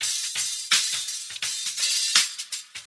[ACD] - Live HiHat Loop (3).wav